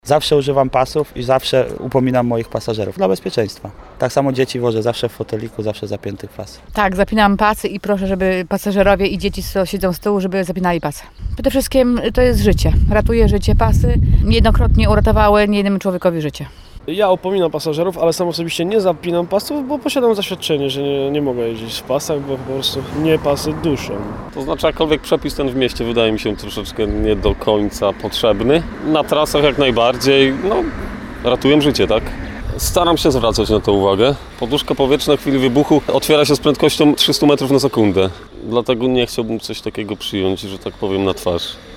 Z kierowcami rozmawiała